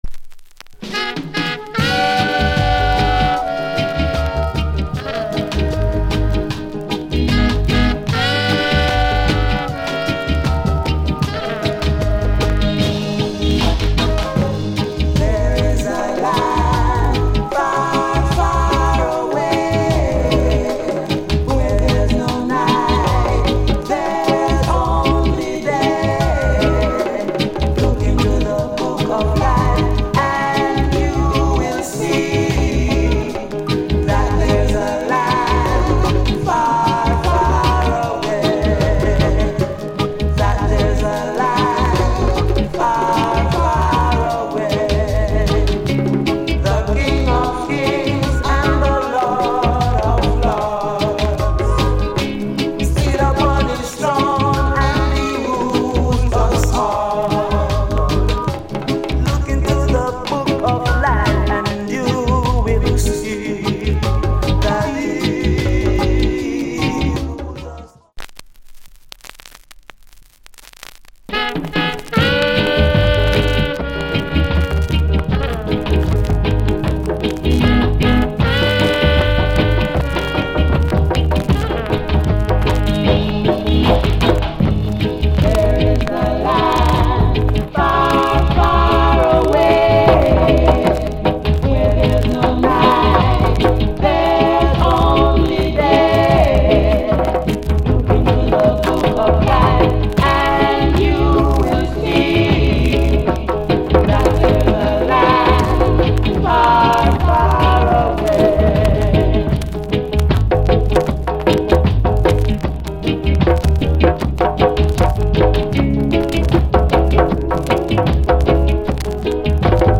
Genre Roots Rock / Group Vocal
* Roots Foundation